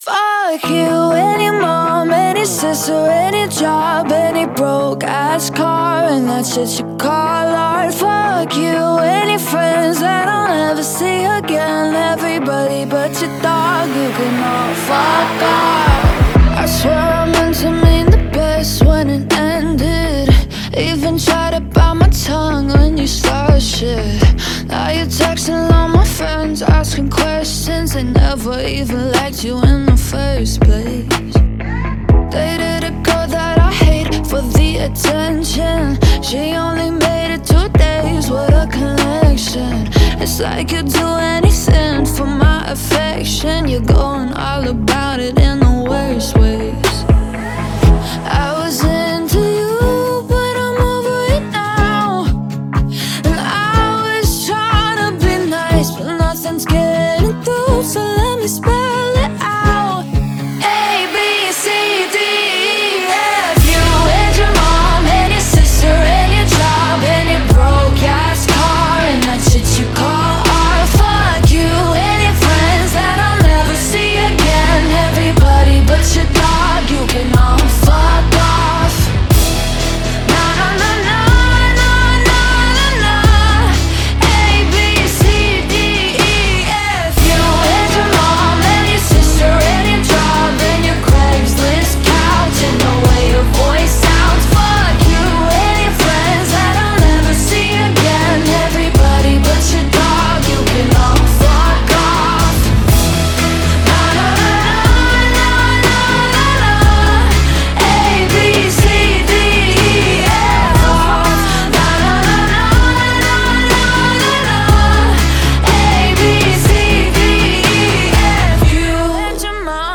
BPM118-122
Audio QualityMusic Cut